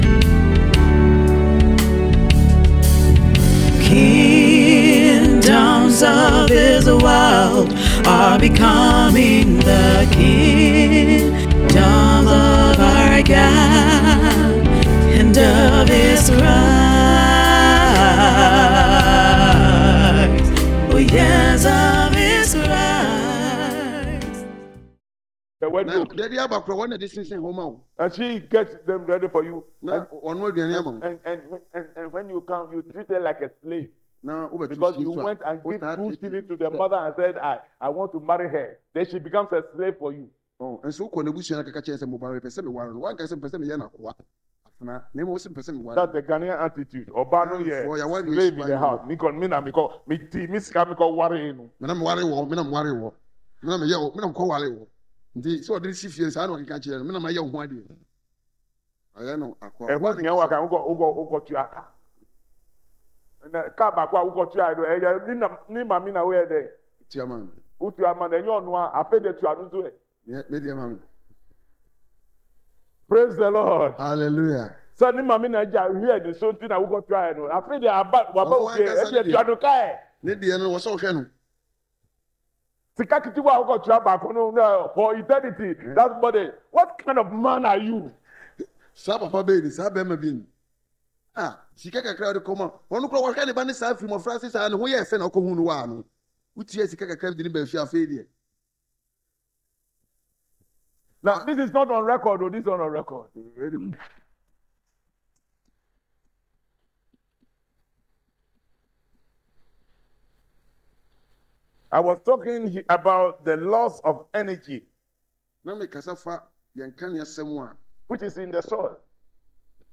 October 25, 2025 BEHOLD THE LAMB OF GOD Series: Audio Sermon Title: Behold The Lamb of God .